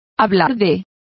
Complete with pronunciation of the translation of discuss.